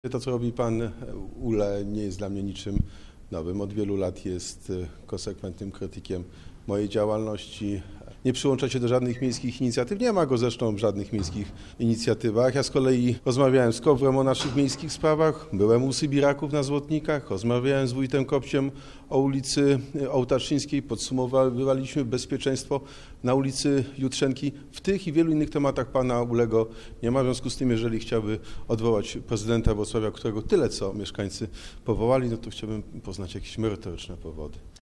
Na zarzuty Piotra Uhle odpowiedział Prezydent Wrocławia Jacek Sutryk.